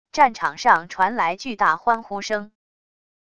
战场上传来巨大欢呼声wav音频